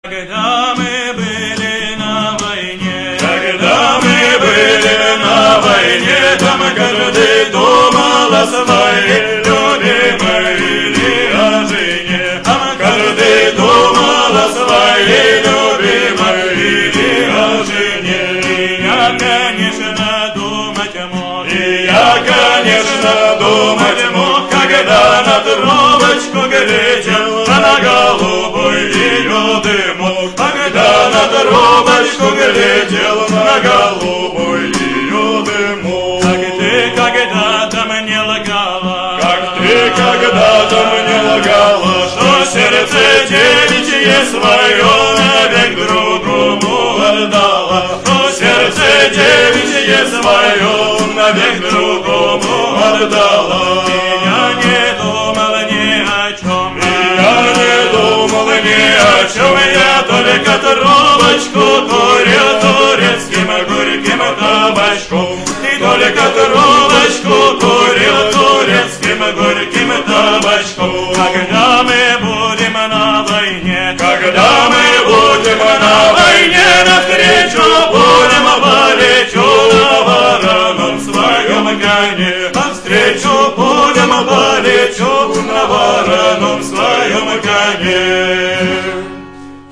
А я была на этом концерте.)
А капелла, в два голоса.